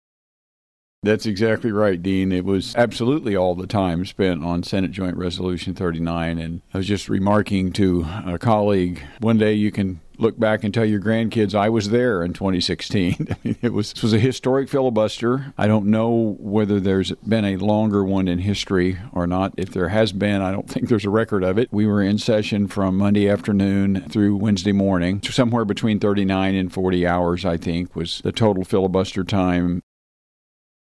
The following cuts are taken from the above interview with Sen. Emery, for the week of March 7, 2016.